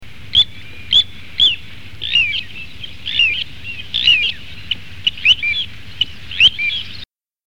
Petit Gravelot
Charadrius dubius